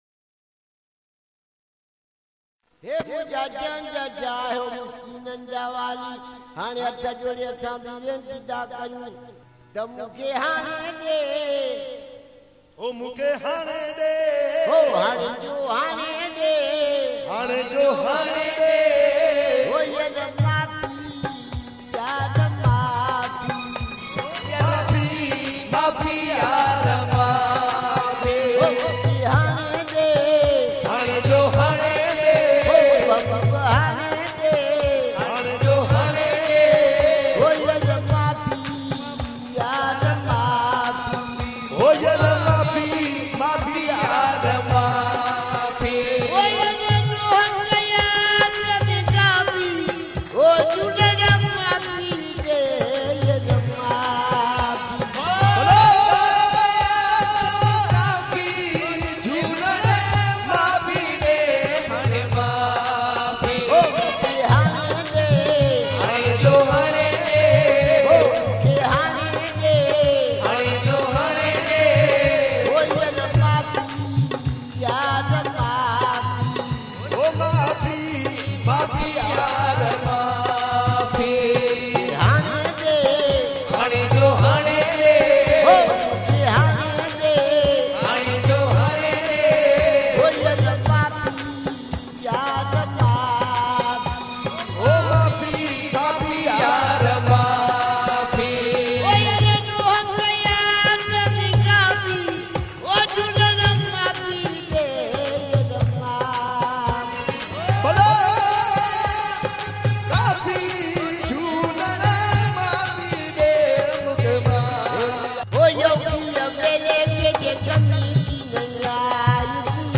recorded at Puj Chaliha Sahib Jhulelal Mandir